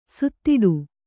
♪ suttiḍu